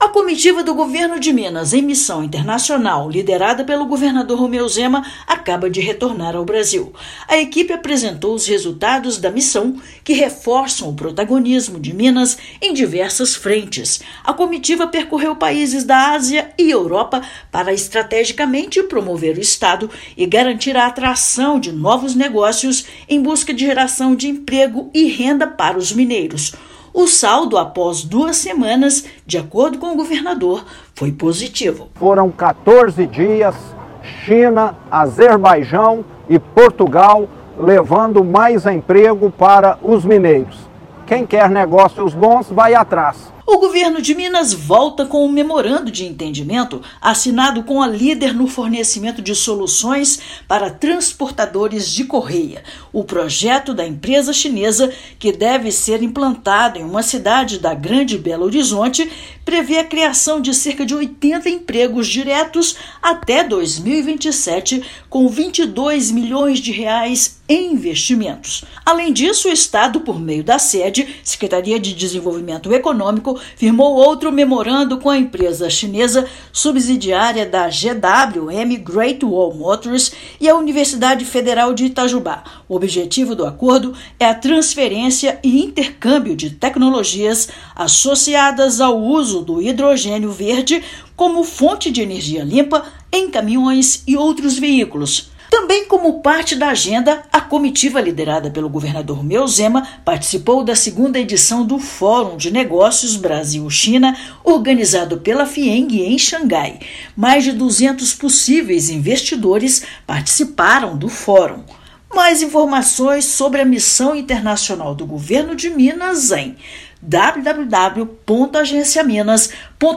[RÁDIO] Governo de Minas retorna de missão internacional com investimento de R$ 22 milhões e destaques no turismo e desenvolvimento sustentável
Na China, Estado atraiu novos investidores e fortaleceu conexões; na COP29, foi anunciada nova meta pela neutralização de carbono e, em Portugal, comitiva reforçou Minas como destino turístico e de empreendimentos inovadores. Ouça matéria de rádio.